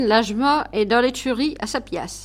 Mots Clé chevalin, cheval, chevaux ; Localisation Île-d'Olonne (L')
Catégorie Locution